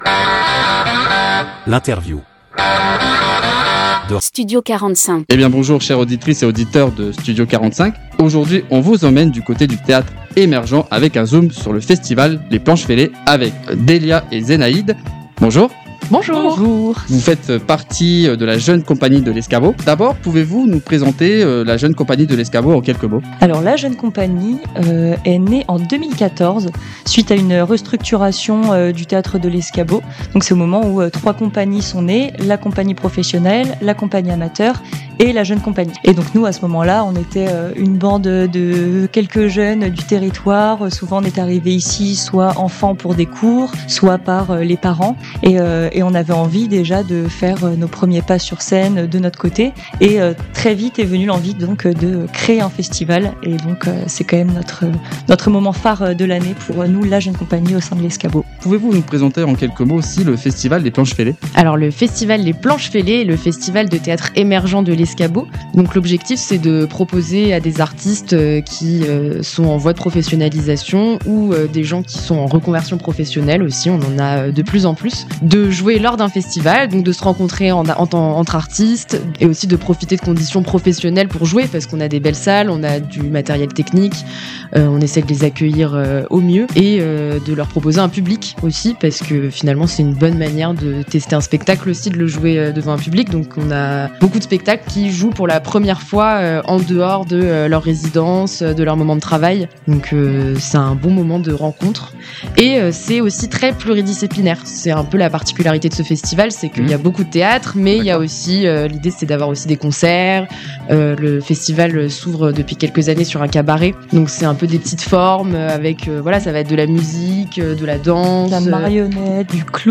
Interview de Studio 45